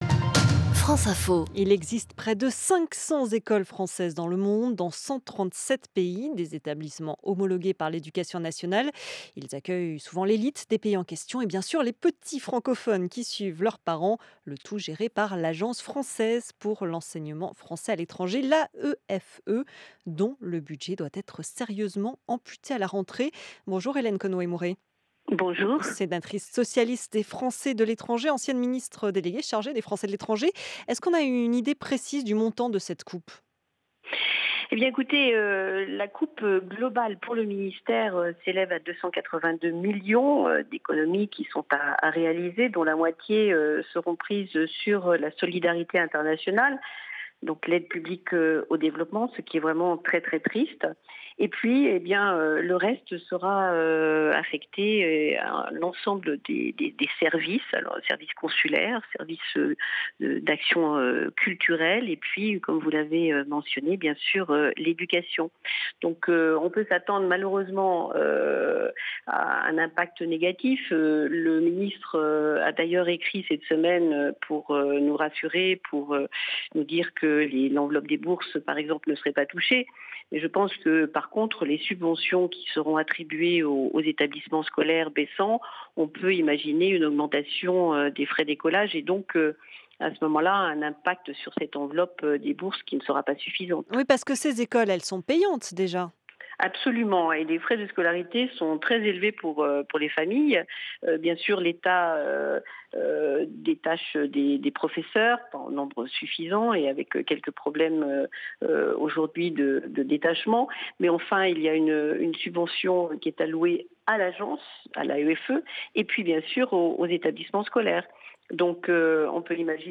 Mon interview sur les coupes budgétaires liées aux affaires étrangères - Hélène Conway-Mouret